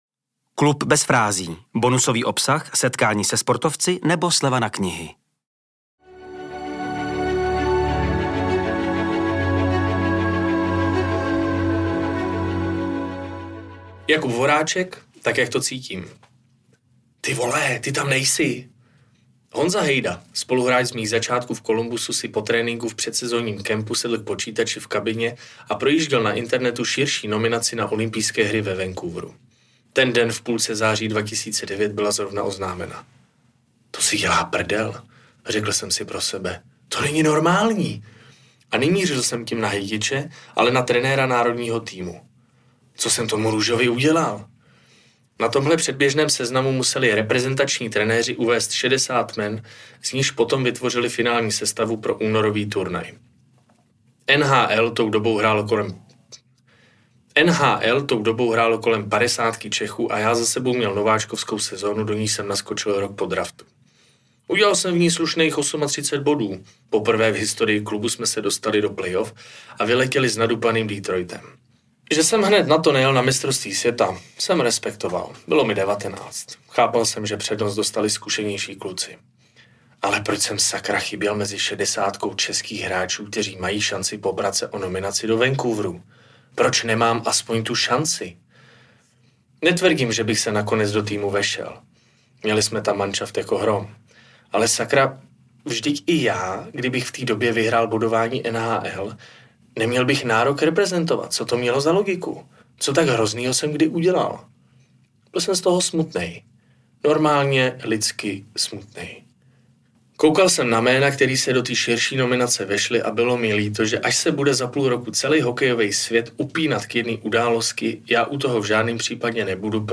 Dopřejte si příběh mistra světa, který v NHL odehrál 1058 zápasů a byl hvězdou v Columbusu i Philadelphii. Upřímné vyprávění Bez frází vám nově načetl jedinečný Jakub Štáfek .